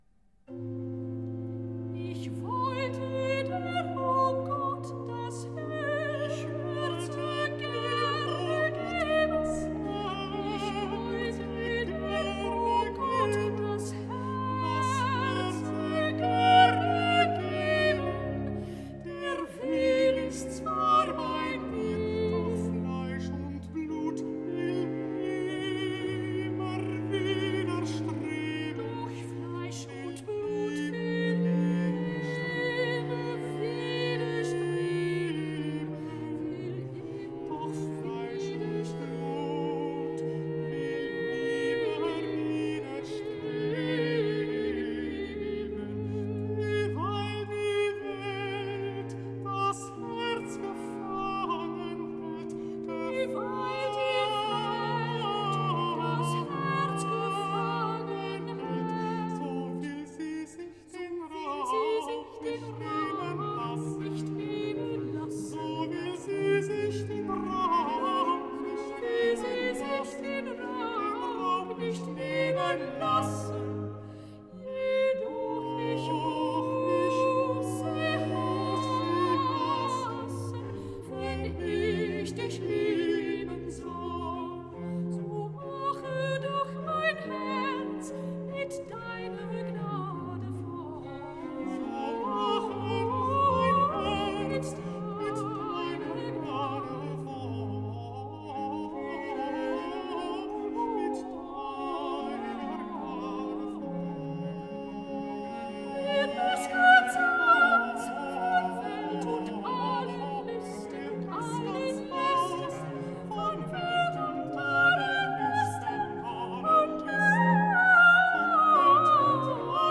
Recitativo - Soprano, Alto